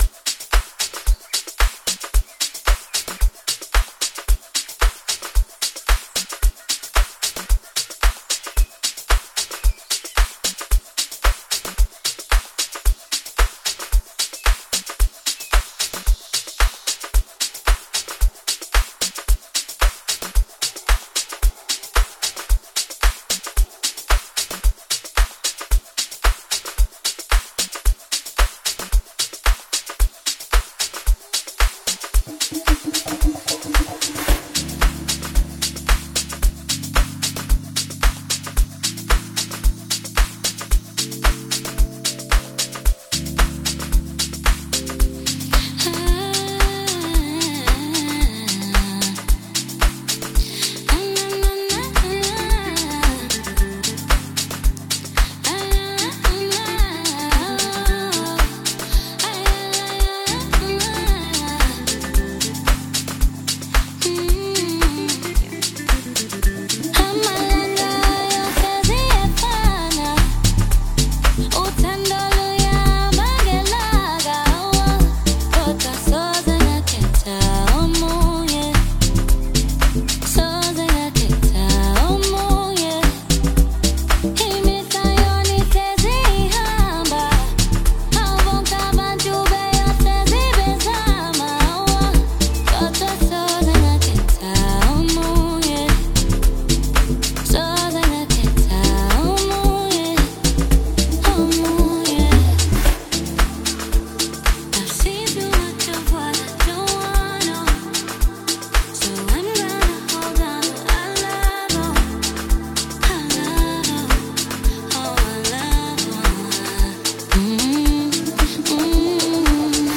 Renowned Uprising South African Amapiano producer